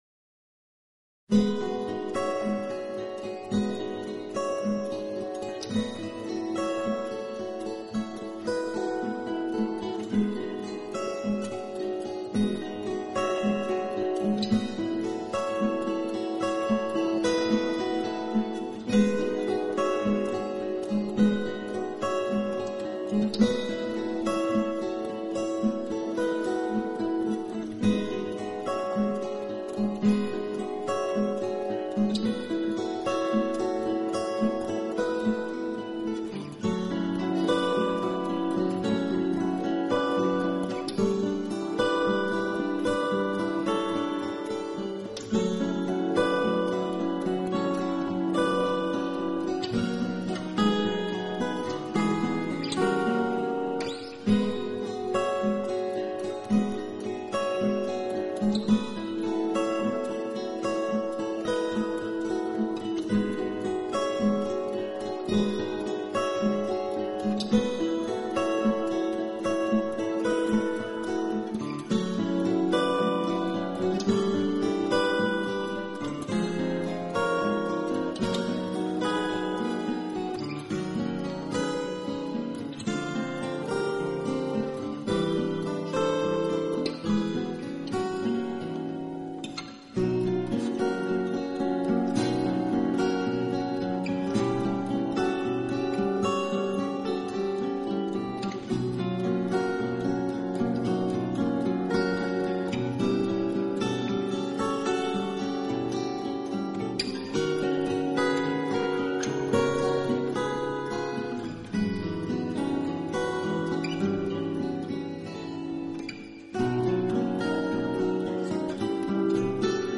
全碟共有乐曲8首，总播唱时间只有39分钟，8首乐曲之中，只有两首吉他独奏，
木吉他，透过出色的录音，象水珠般，那晶莹通透的吉他琴音中，竟夹杂着一般
湿润的自然美，弹跳力之中又渗透着无敌之张力，是同类录音中少有。